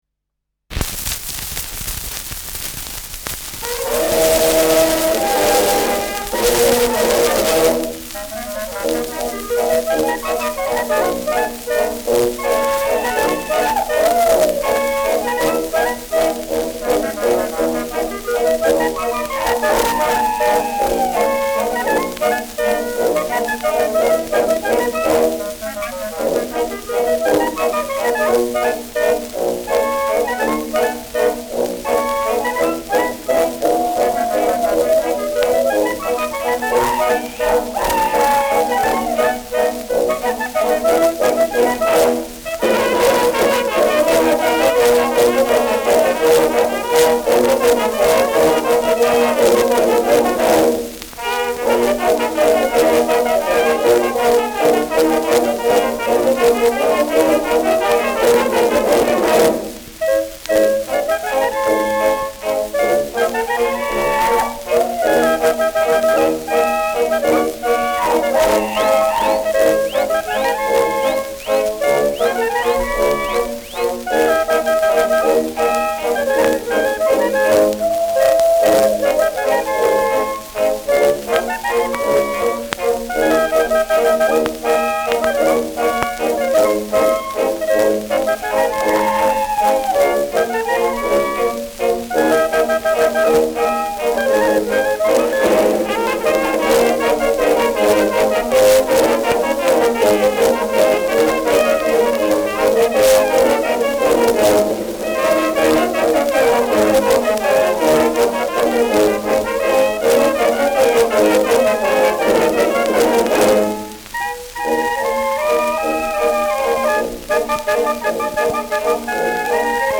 Schellackplatte
präsentes Rauschen